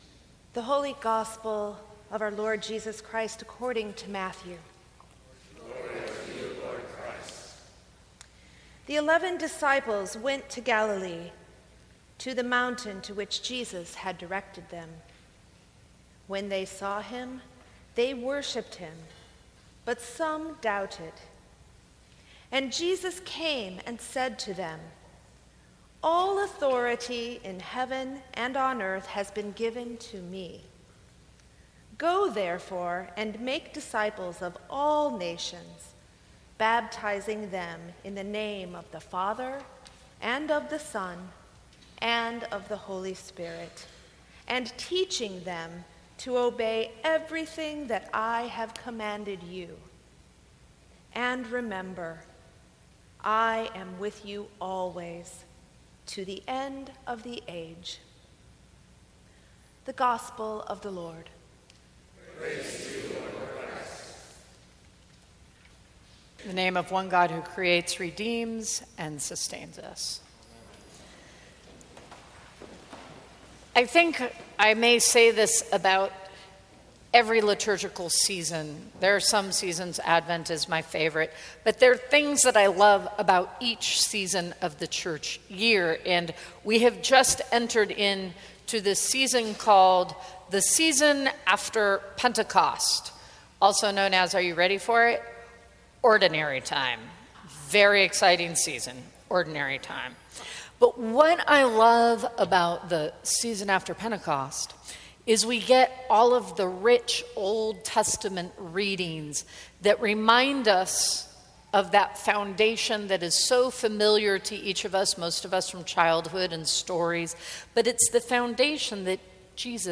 Sermons from St. Cross Episcopal Church We Are Relationship Jun 12 2017 | 00:16:01 1x 00:00 / 00:16:01 Subscribe Share Apple Podcasts Spotify Overcast RSS Feed Share Link Embed